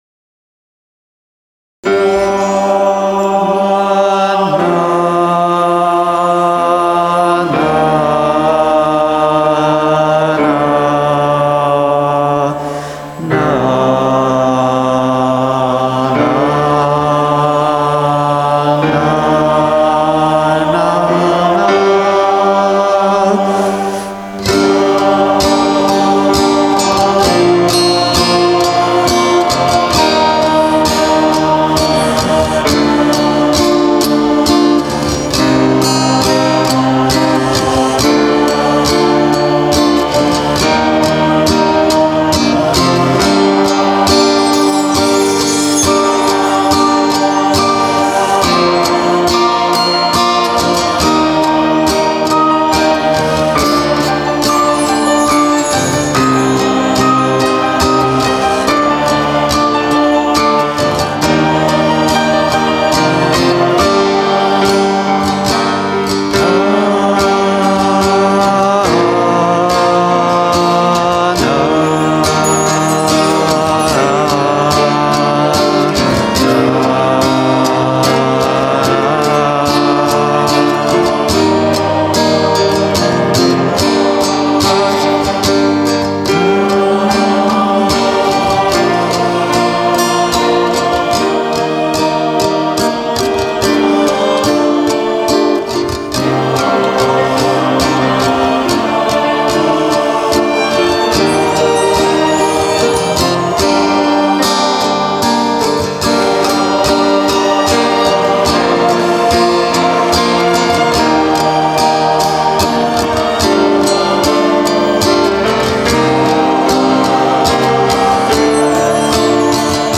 St. James Westminster, London, Canada Church Services